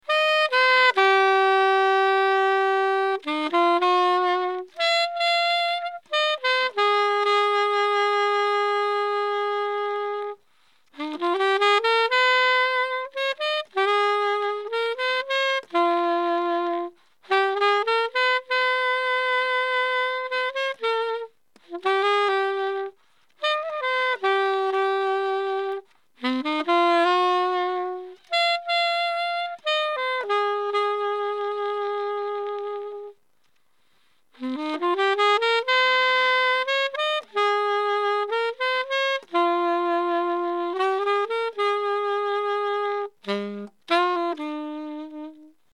サンプル音源1　IWサテンアルト